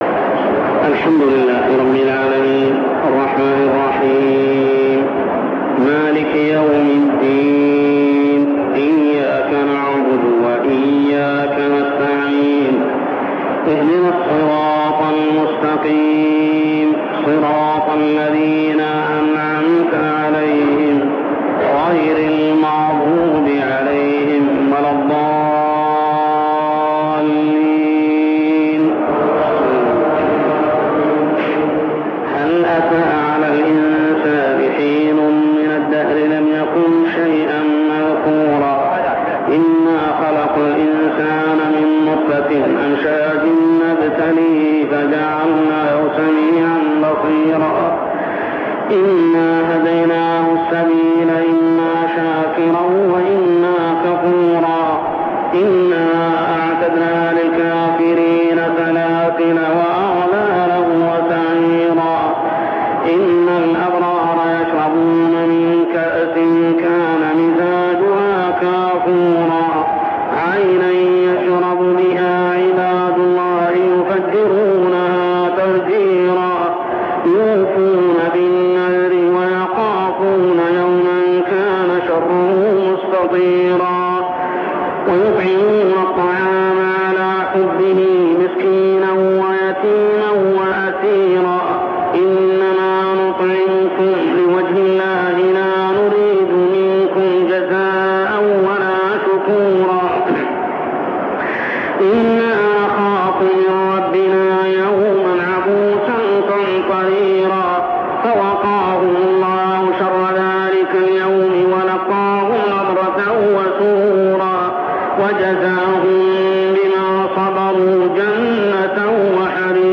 صلاة التراويح عام 1402هـ من سورة الإنسان كاملة حتى سورة الغاشية كاملة | Tarawih prayer from Surah Al-Insaan to surah Al-Ghashiyah > تراويح الحرم المكي عام 1402 🕋 > التراويح - تلاوات الحرمين